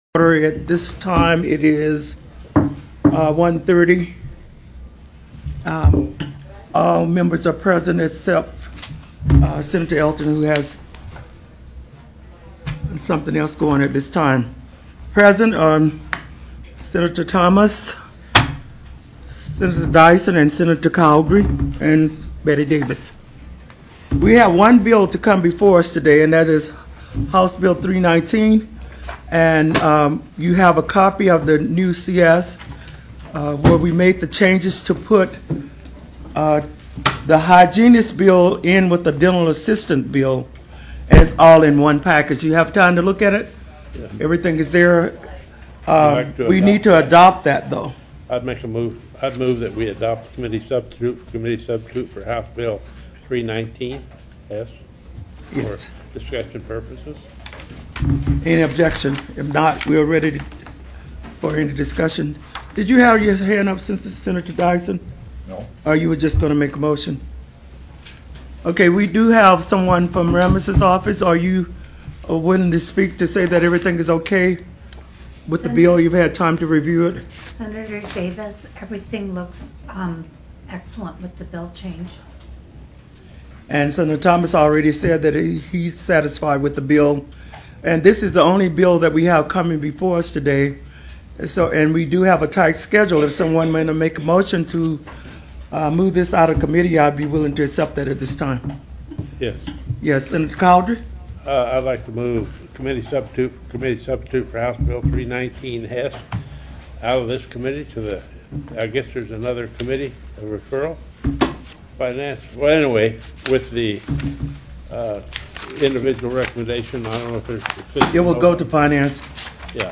04/02/2008 01:30 PM Senate HEALTH, EDUCATION & SOCIAL SERVICES
+= HB 319 DENTISTS & DENTAL ASSISTANTS TELECONFERENCED